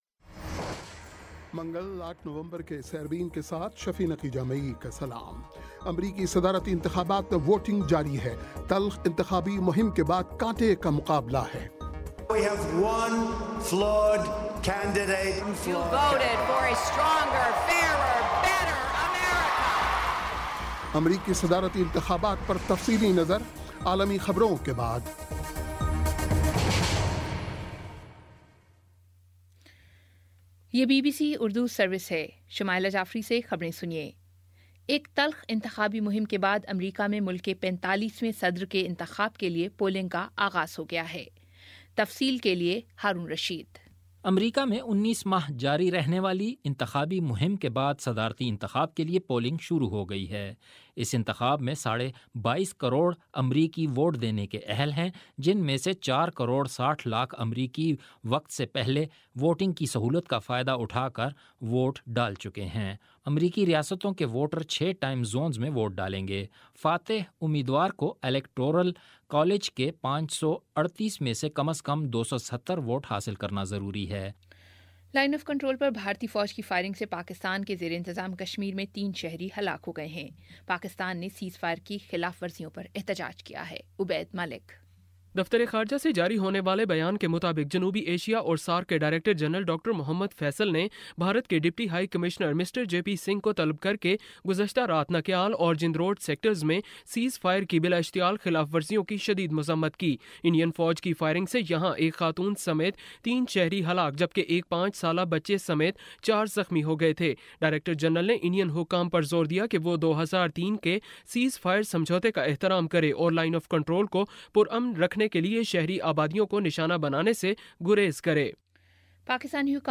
منگل08 نومبر کا سیربین ریڈیو پروگرام